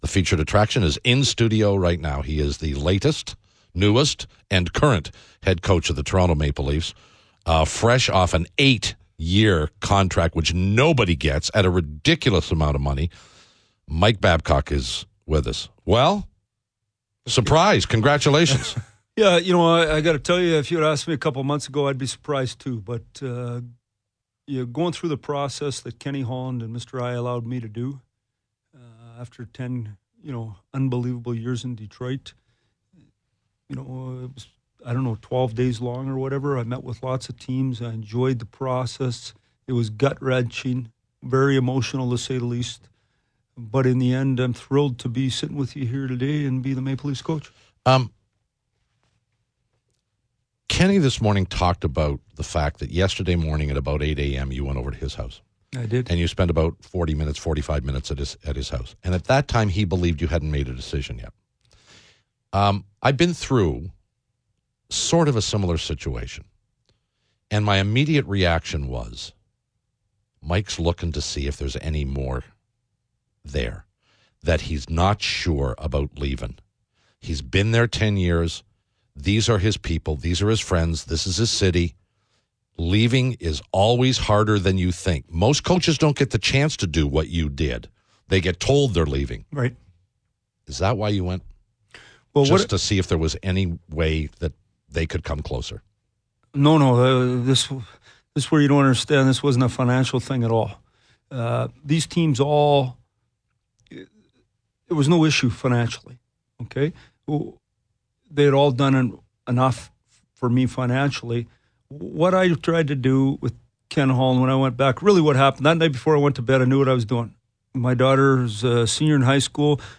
Here's the interview in full: